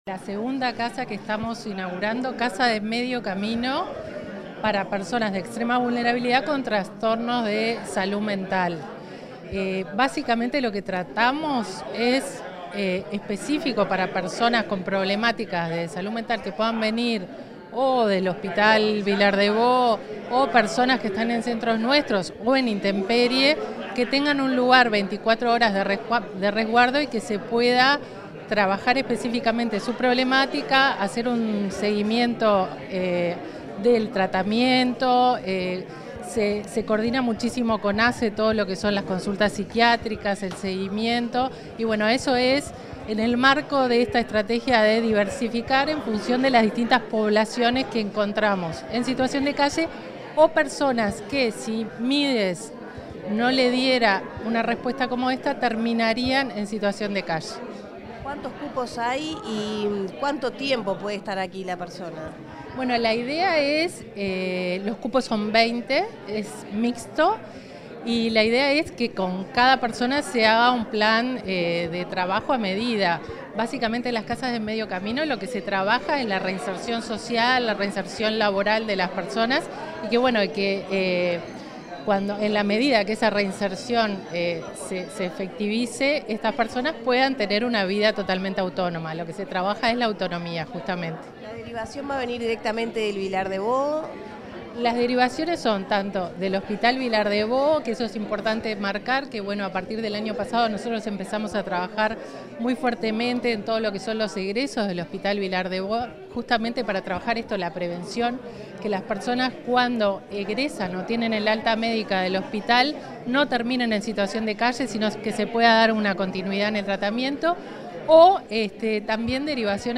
Declaraciones de la directora de Protección Social, Fernanda Ausperg
Declaraciones de la directora de Protección Social, Fernanda Ausperg 24/07/2023 Compartir Facebook X Copiar enlace WhatsApp LinkedIn La directora de Protección Social, del Ministerio de Desarrollo Social (Mides), Fernanda Auersperg, explicó a la prensa las características de la segunda casa de medio camino de salud mental, inaugurada este lunes 24 en Montevideo.